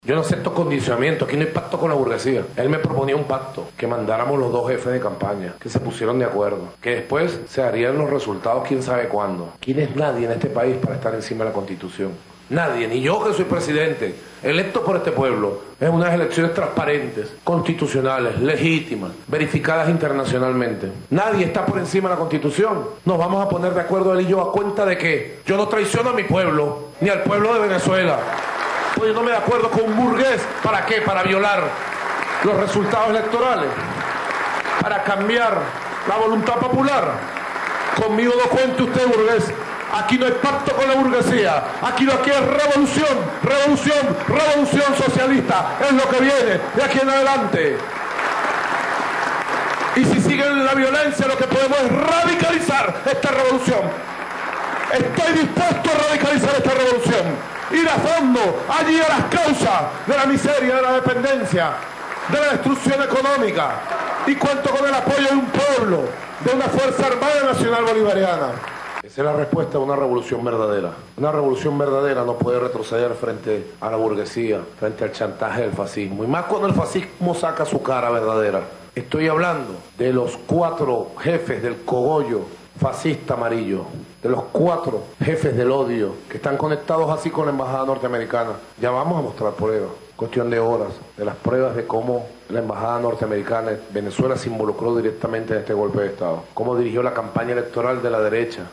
Declaraciones de Nicolás Maduro